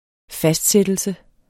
Udtale [ ˈfasdˌsεdəlsə ]